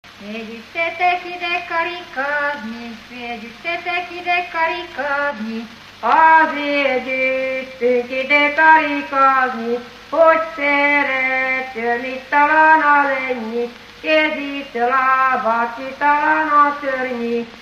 Felföld - Nyitra vm. - Menyhe
ének
Műfaj: Lakodalmas
Stílus: 7. Régies kisambitusú dallamok
Szótagszám: 8.8.8.8
Kadencia: 1 (1) 1 1